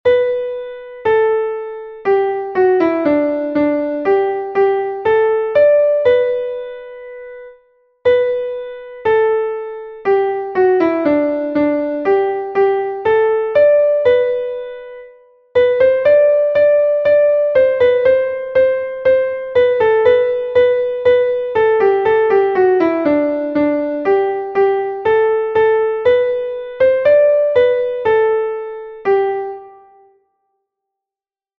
Text und Melodie: Hessisches Volkslied (18. Jahrhundert)